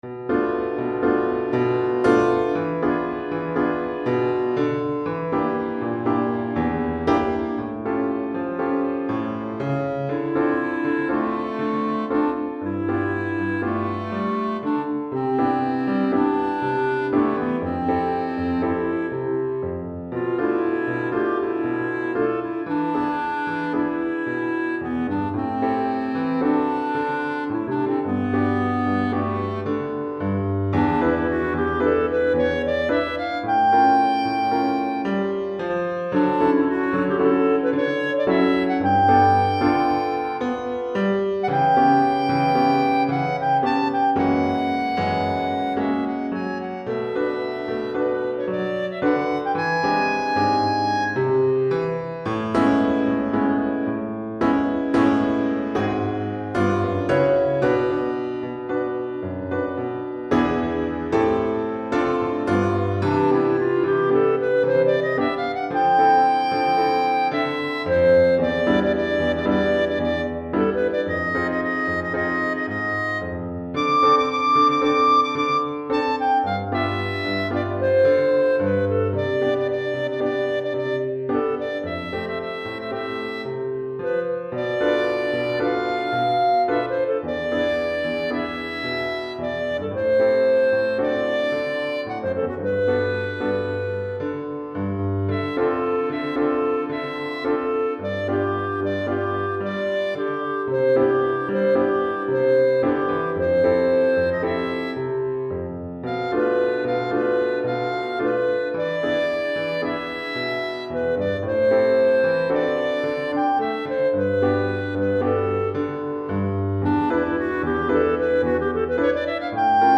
Clarinette en Sib et Piano